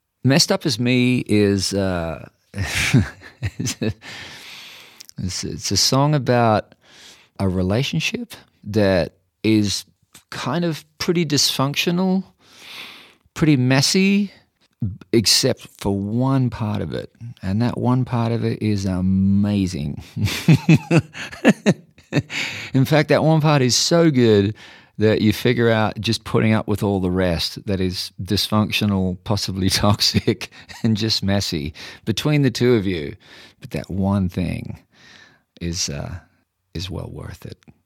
Keith Urban talks about his new single, "Messed Up As Me."